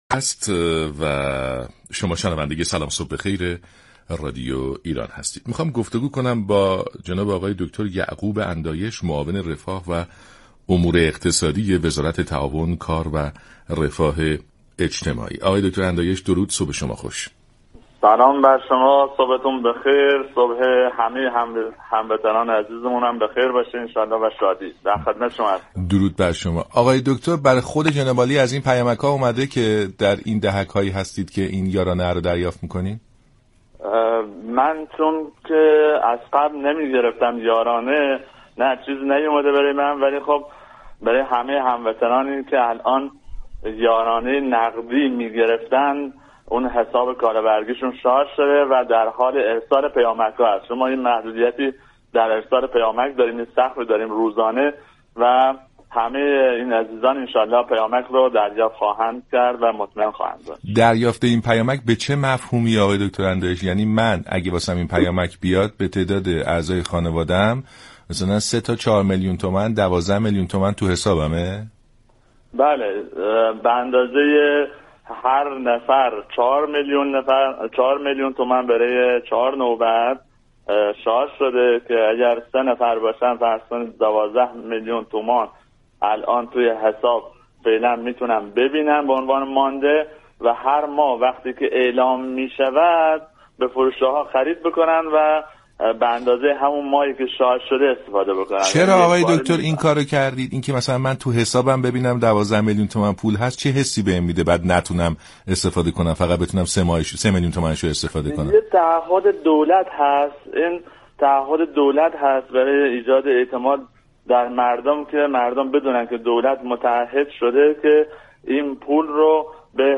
معاون رفاه و امور اقتصادی وزارت تعاون، كار و رفاه اجتماعی در برنامه سلام‌صبح‌بخیر گفت: كالابرگ یارانه‌بگیران برای چهارمرحله شارژ شده و هر نفر 4 میلیون دریافت كرده‌اند.
برنامه سلام‌صبح‌بخیر شنبه تا پنج‌شنبه ساعت 6:35 از رادیو ایران پخش می‌شود.